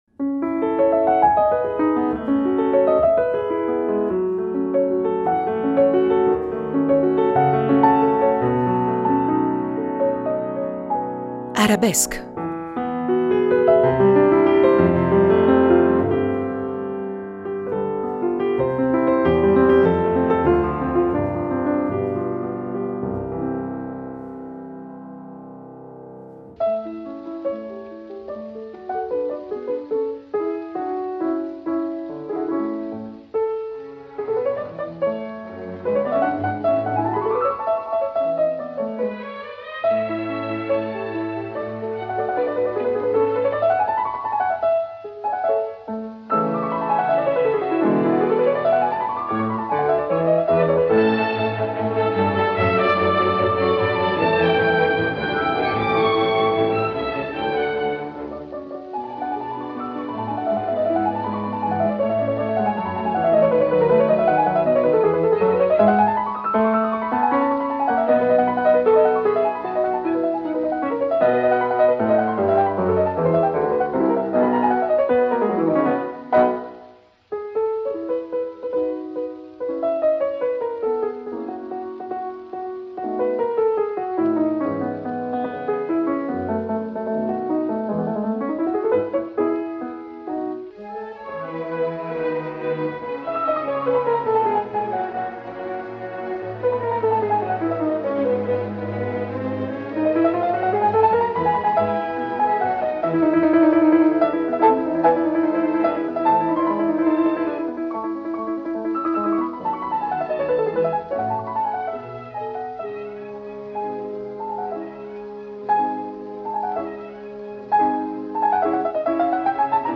Prima parte, incontro